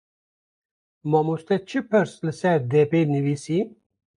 Pronounced as (IPA) /pɪɾs/